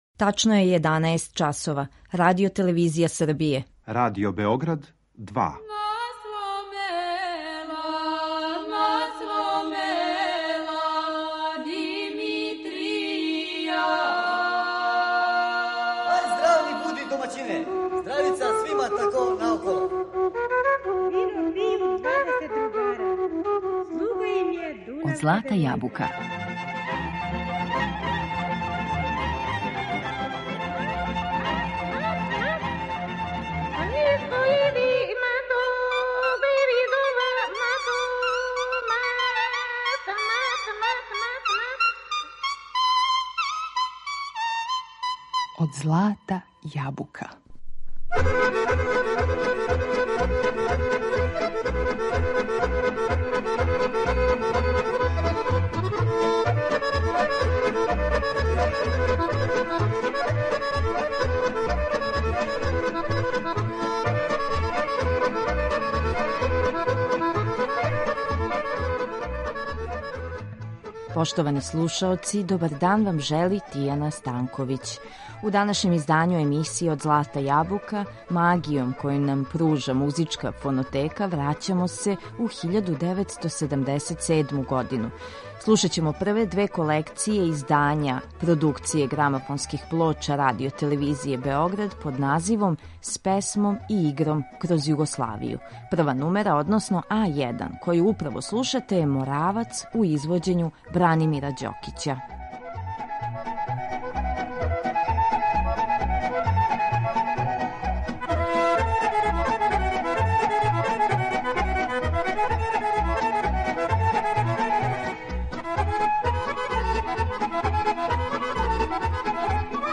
Овај звучни преглед музичких дијалеката тадашње земље објединио је записе најбољих вокалних извођача и народних ансамбала. На репертоару су прве две колекције народних песама и игара.